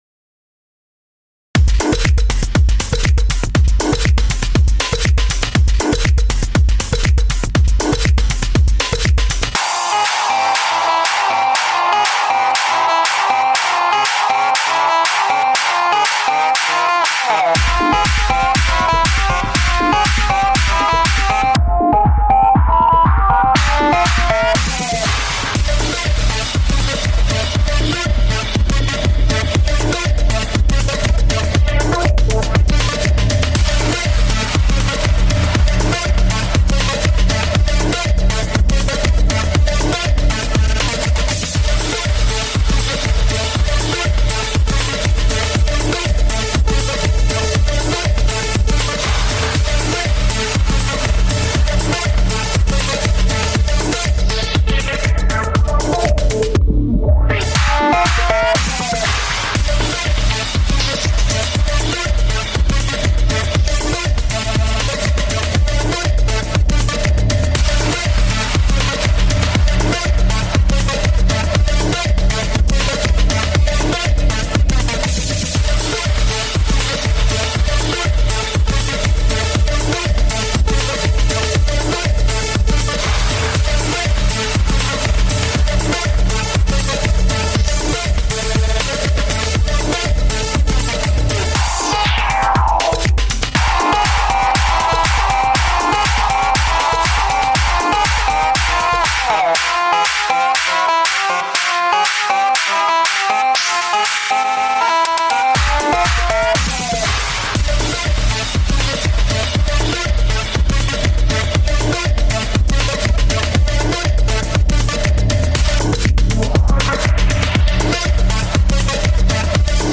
Крутая музыка для подиума 8